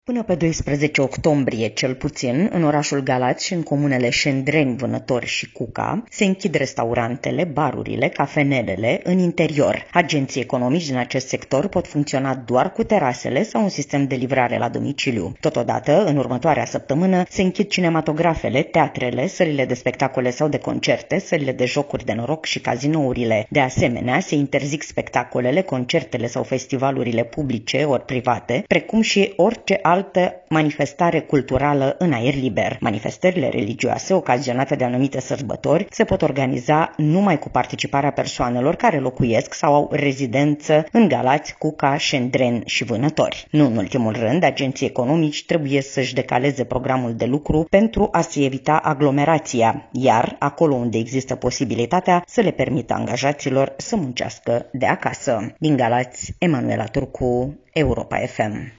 Restaurantele, cinematografele, teatrele și cazinourile din orașul Galați și din trei comune gălățene se închid timp de o săptămână din cauza înmulțirii numărului de cazuri de infectare cu virusul SARS CoV-2, transmite corespondentul Europa FM.